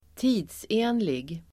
Uttal: [²t'i:dse:nlig]